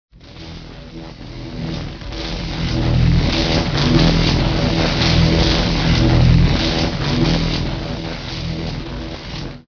electrolyser.ogg